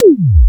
X E-DRUM 3.wav